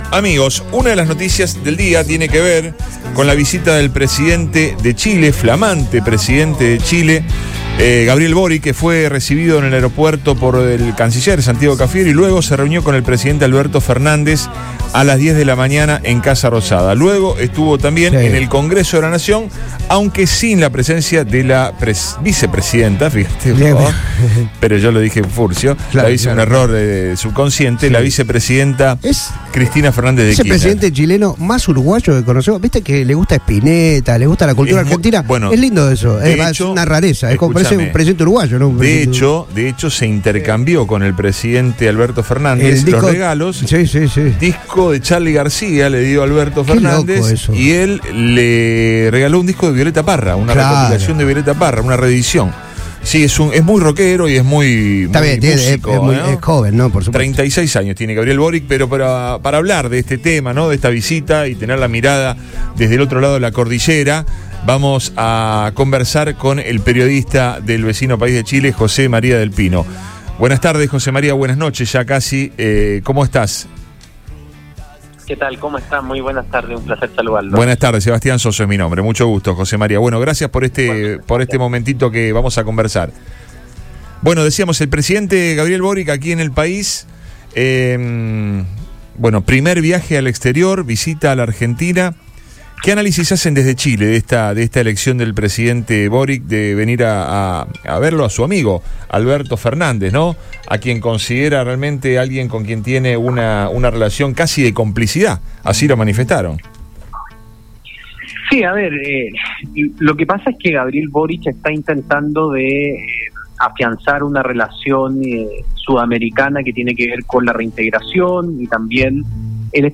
dijo en diálogo con